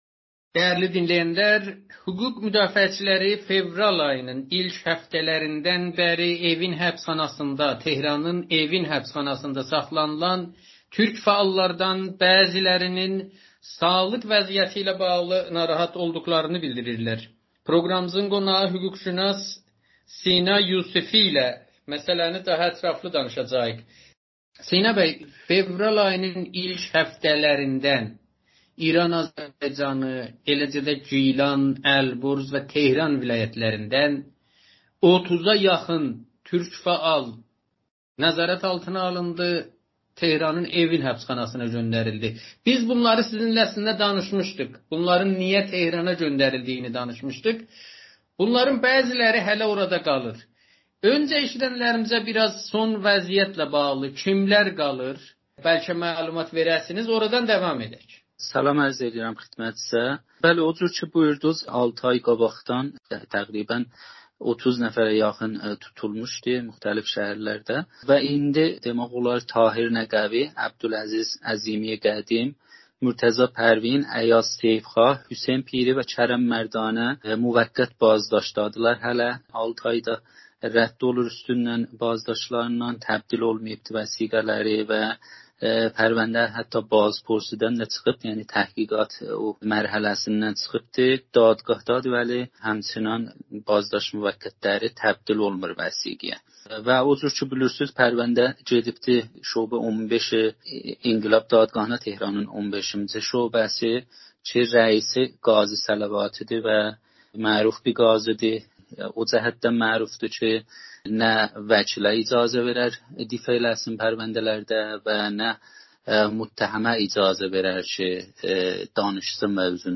müsahibəsində